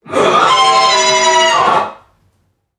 NPC_Creatures_Vocalisations_Robothead [77].wav